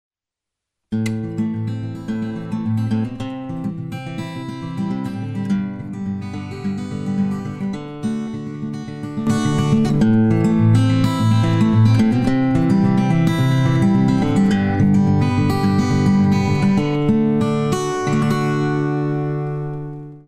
La chitarra acustica guadagna compattezza in basso e accuratezza in alto con la contemporanea attenuazione delle escursioni dinamiche, lo stesso vale per la chitarra elettrica pulita.
AcoGuitar
Le tracce sono state elaborate inserendo/escludendo il compressore in modo da far apprezzare le differenze e il contributo dell’effetto sul suono.
AcoGuitar.mp3